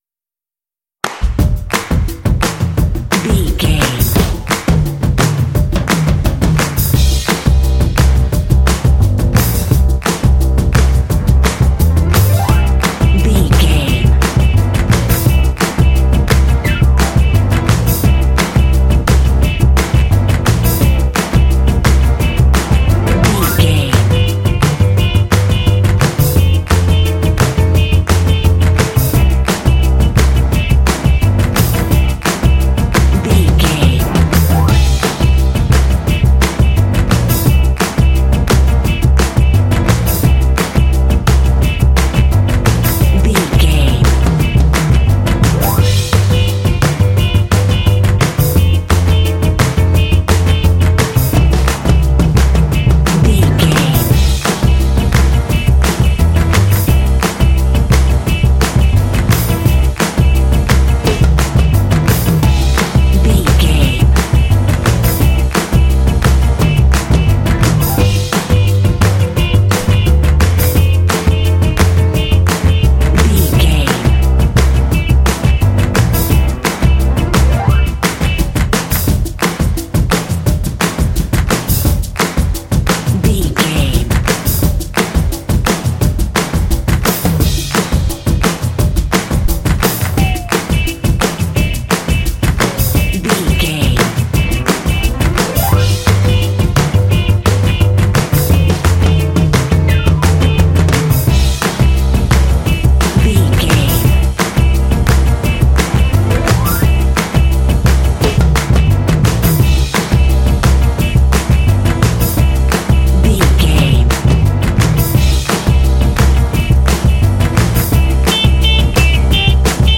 Ionian/Major
groovy
bouncy
drums
percussion
bass guitar
piano
electric guitar
blues
jazz